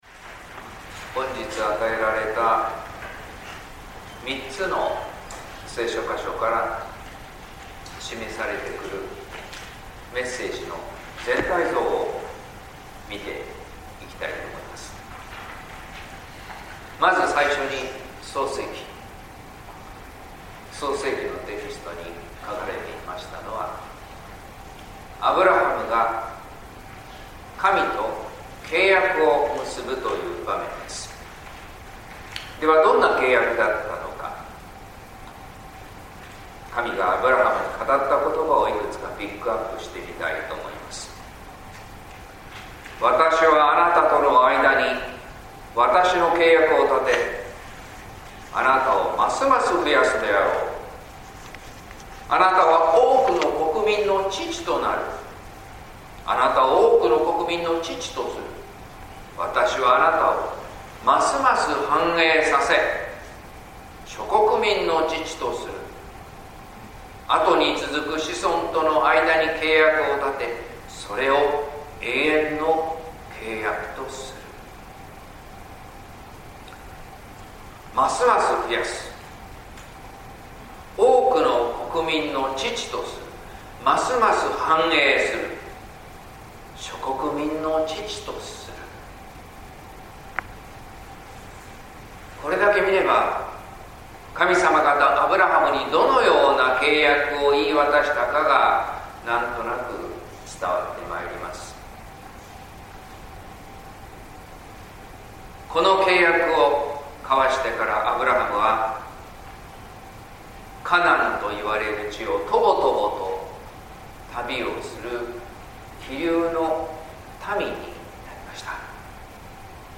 説教「神のことを思う」（音声版）